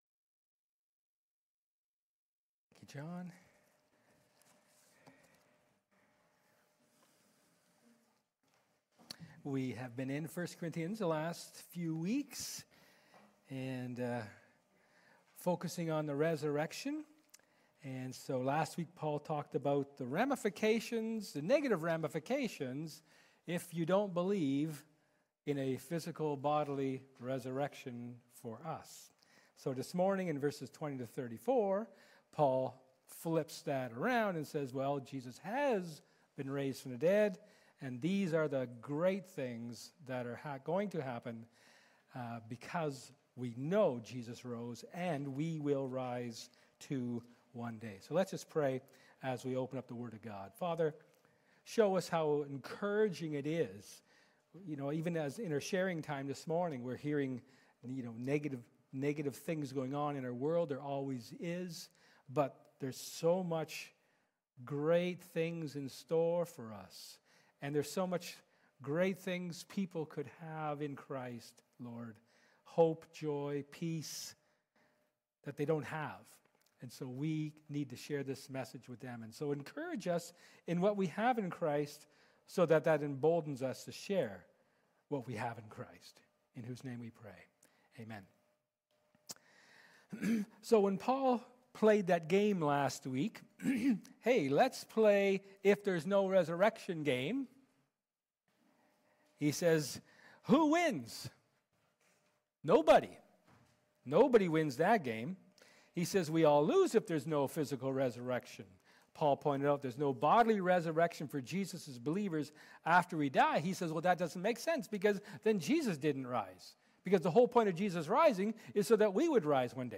Service Type: Sermon
April-6-2025-sermon.mp3